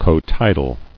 [co·tid·al]